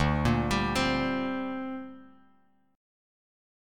D7sus4#5 Chord